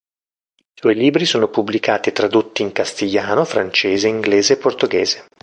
por‧to‧ghé‧se
/por.toˈɡe.ze/